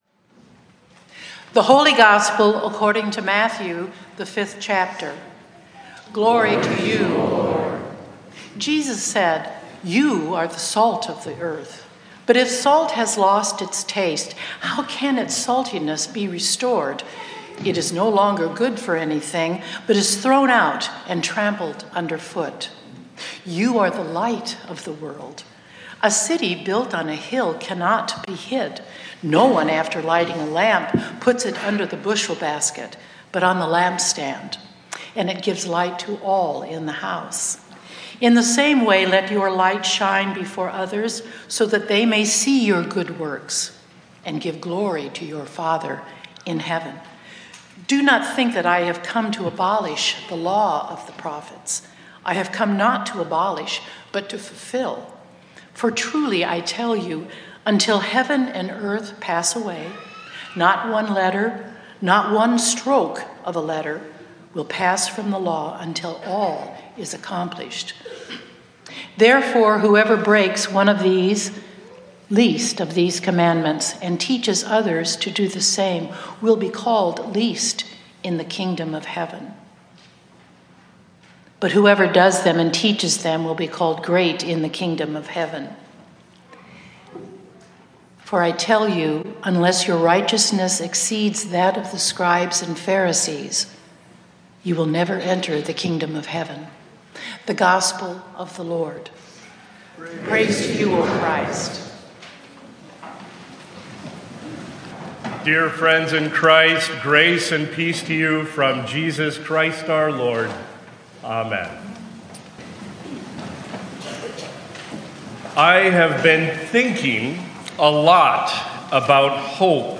Includes sermons from our Sunday morning 9:45 worship services.